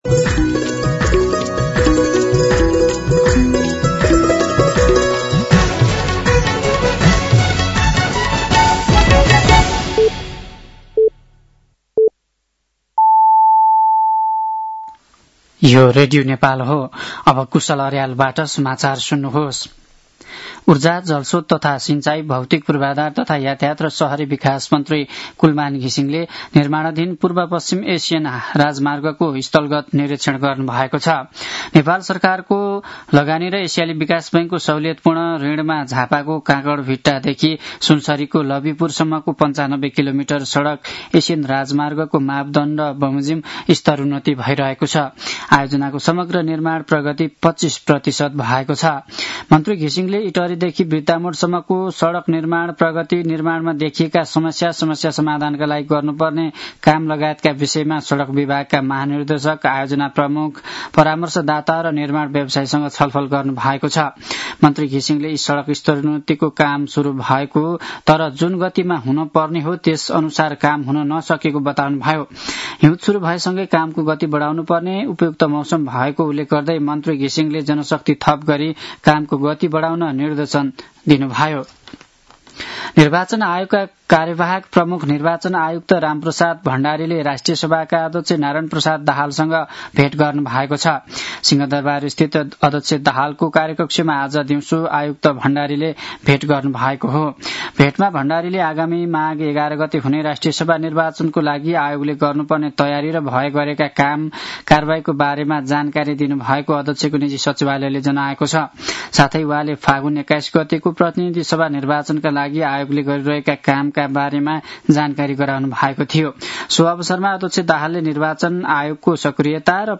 साँझ ५ बजेको नेपाली समाचार : १५ मंसिर , २०८२
5p-news-8-15.mp3